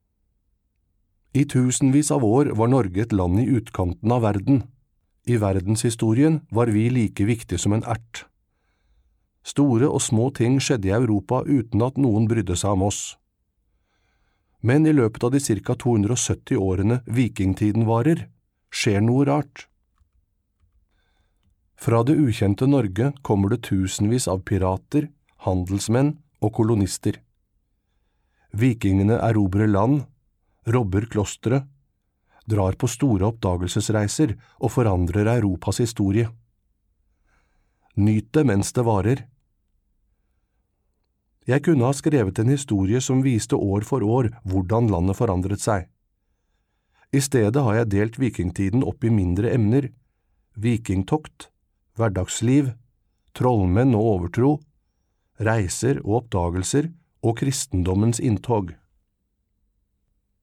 Nedlastbar lydbok